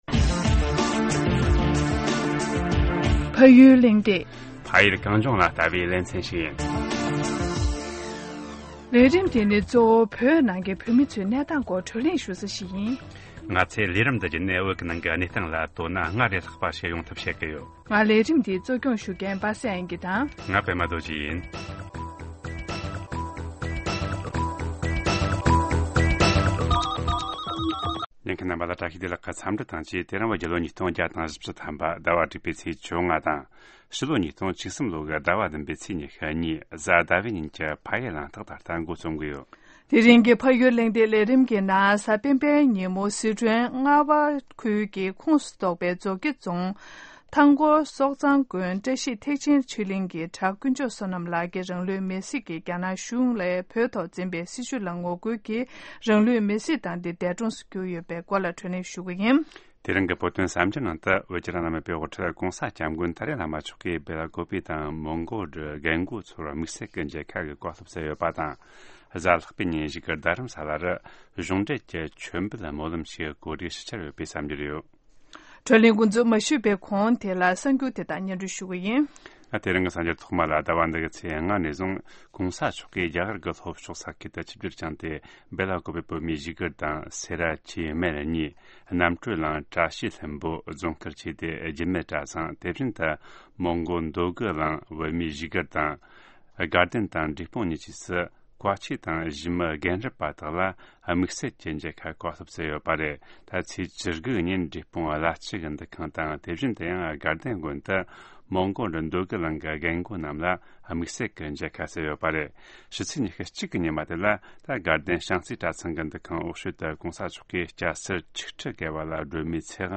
བགྲོ་གླེང་ཞུས་པ་ཞིག་གསན་གྱི་རེད།